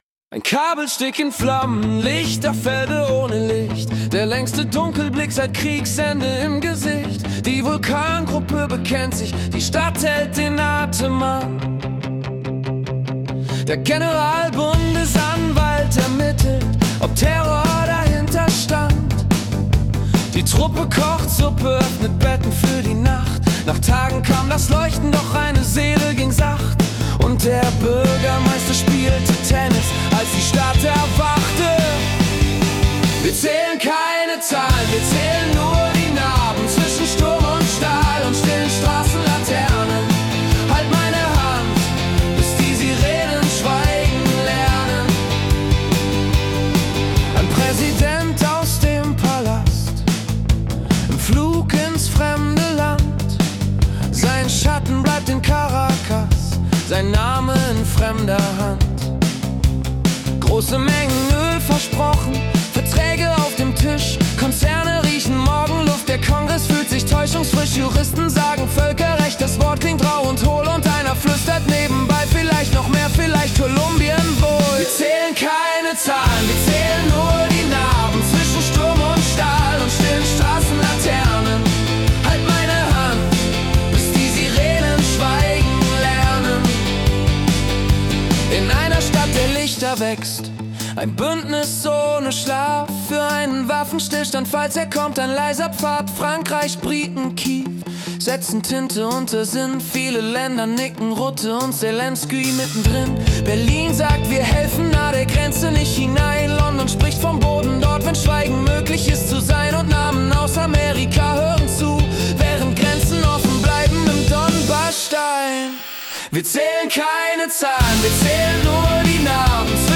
Januar 2026 als Singer-Songwriter-Song interpretiert.